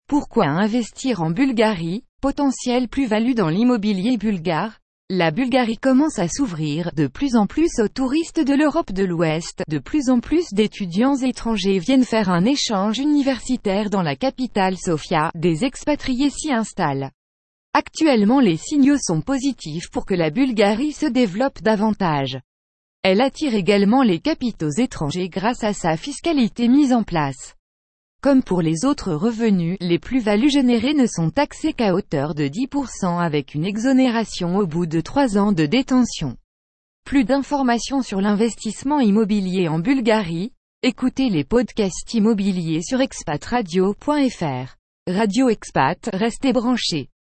Nous vous conseillons d'écouter notre spot audio pour en savoir plus et nous contacter pour plus de renseignements